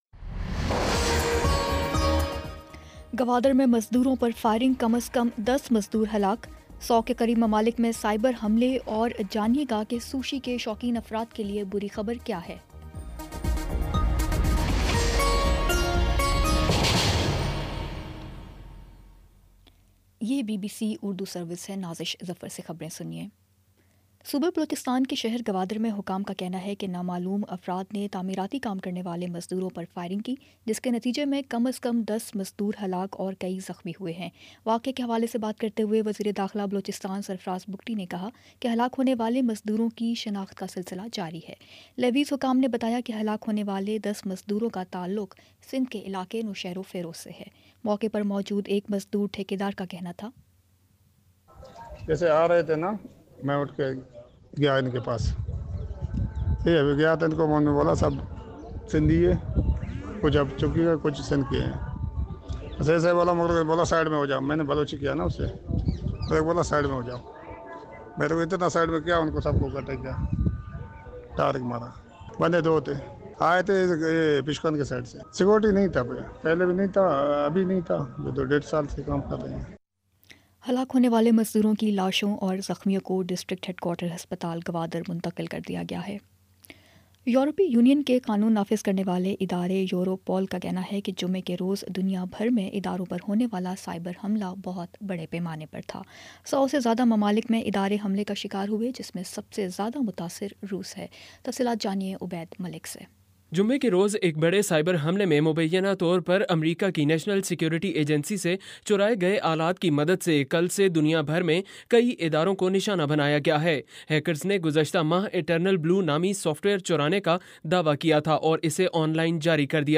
مئی 13 : شام چھ بجے کا نیوز بُلیٹن